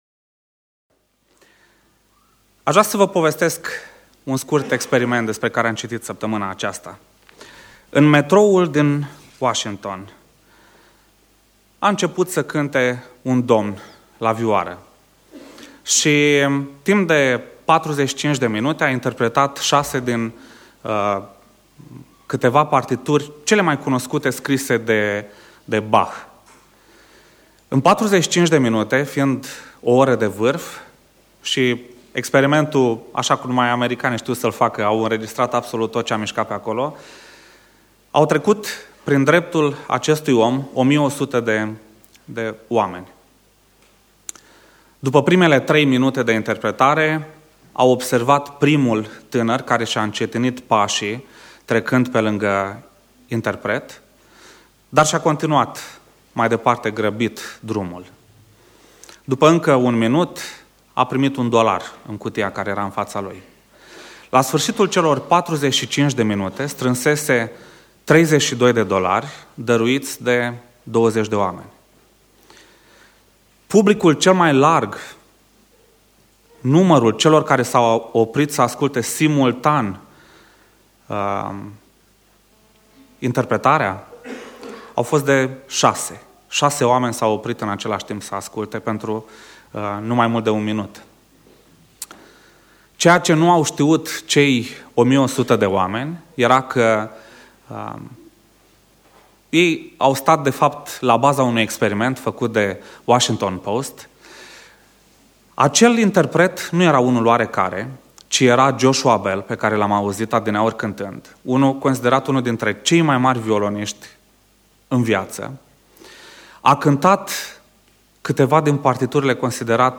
Predica Exegeza - Dragostea